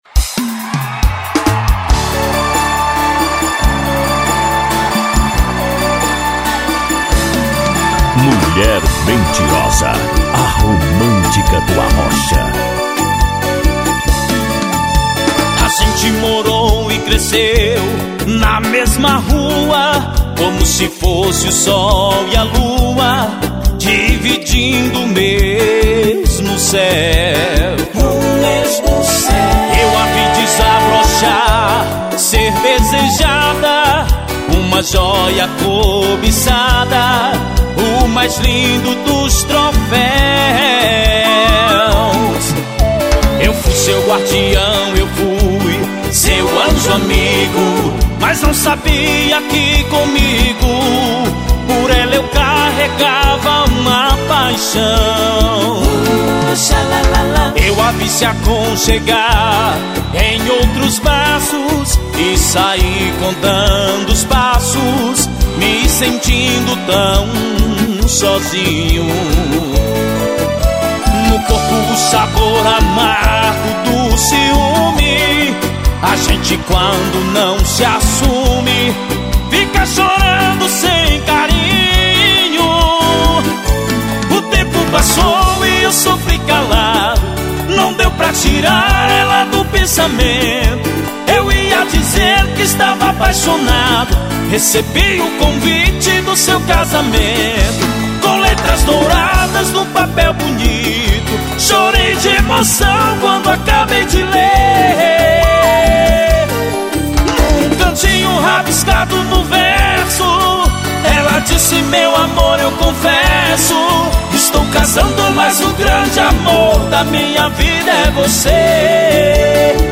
na pegada do arrocha